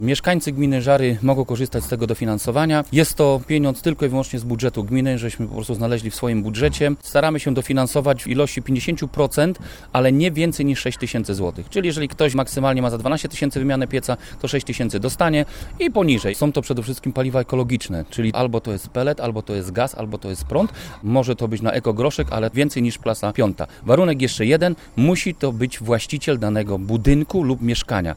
–Trzeba spełnić jednak odpowiednie warunki – powiedział wójt Leszek Mrożek: